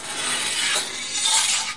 描述：此声音于2013年5月在德国哈恩拍摄。所有声音均以变焦Q3录制。我们已经在这个大机库中击败，扔掉并抛出我们所发现的一切。
标签： 机库 声景 气氛 环境 ATMO 德国 噪声 现场记录 哈恩 气氛
声道立体声